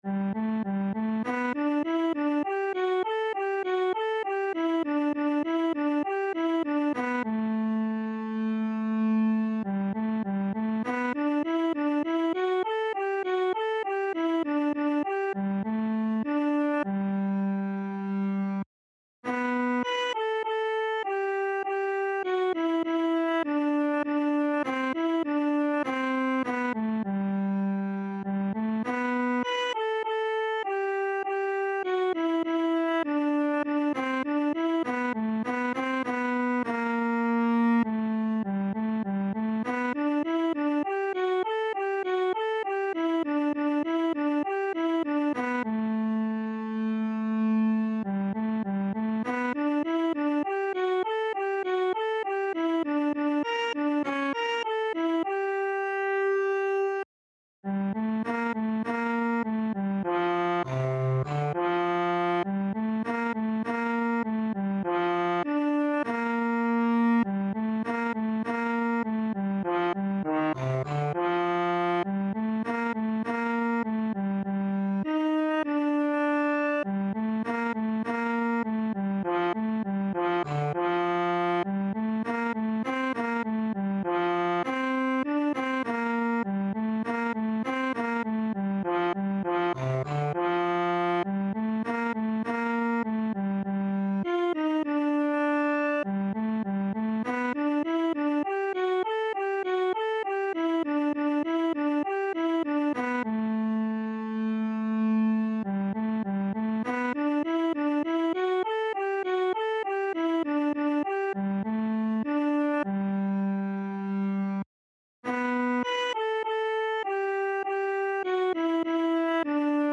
Noty na violoncello.
Aranžmá Noty na violoncello
Hudební žánr Klasický